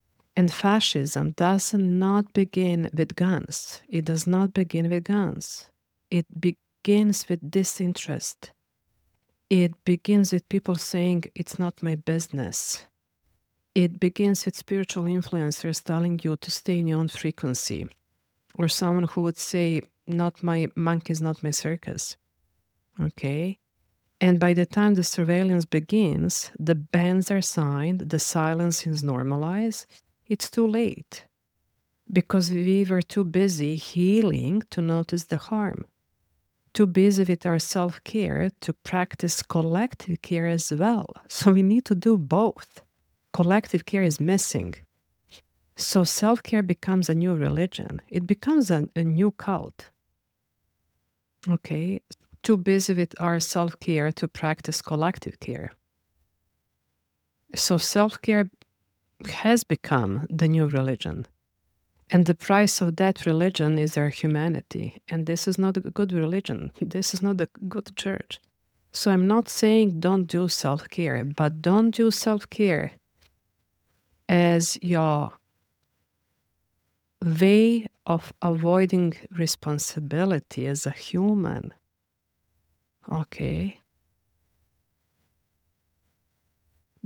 Tone and Voice
• Radical clarity – There’s no soft-pedaling.
• Moral urgency – It’s a wake-up call to a community lulled by comfort and detachment.